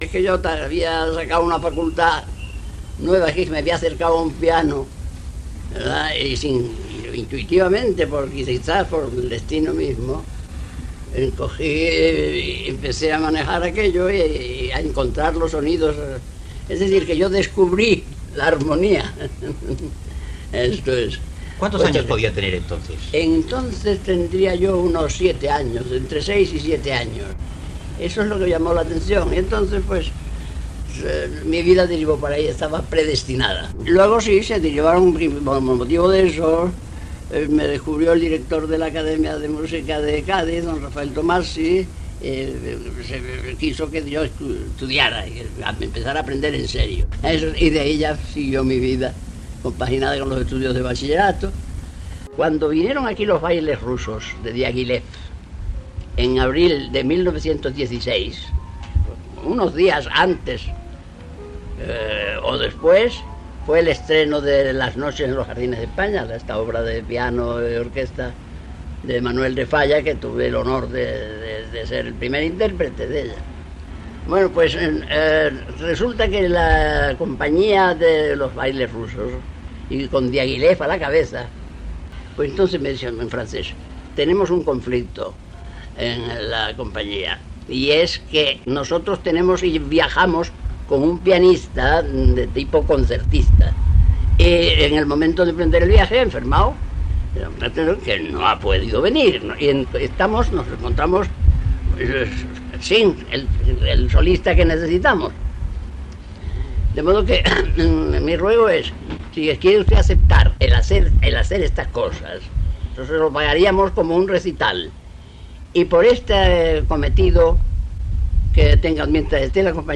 Entrevista al pianista José Cubiles